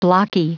Prononciation du mot blocky en anglais (fichier audio)
Prononciation du mot : blocky
blocky.wav